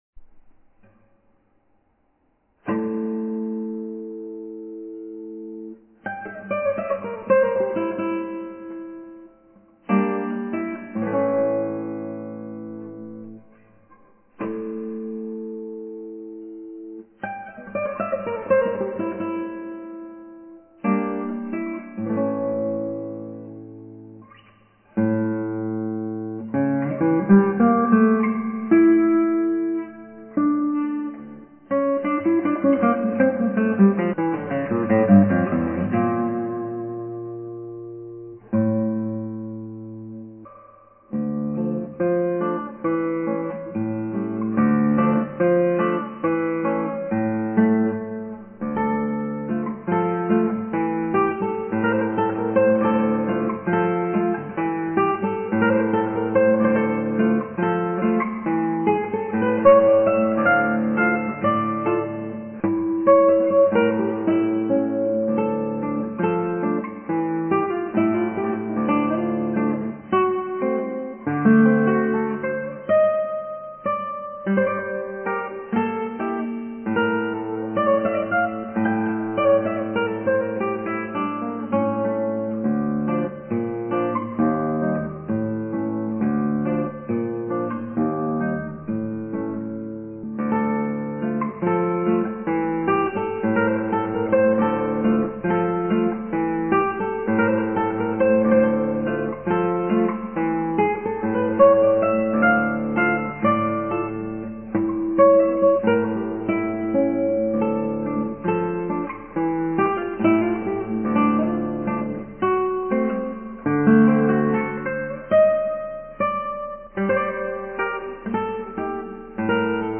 アラビア風奇想曲、バルベロで